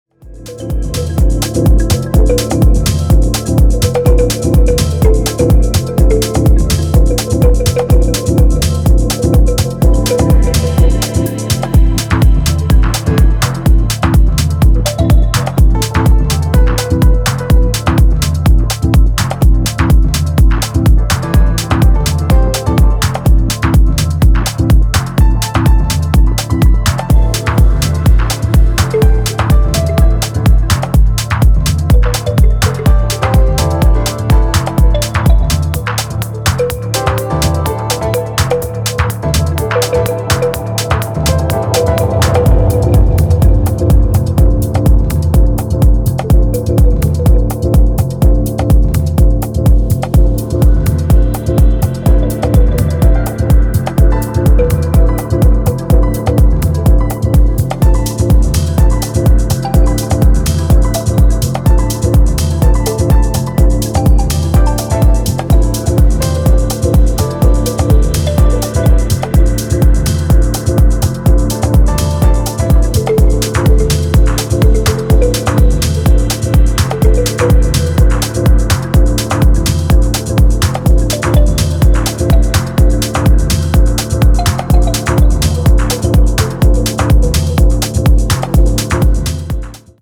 グッド・ディープ・ハウス！
ジャンル(スタイル) DEEP HOUSE / DETROIT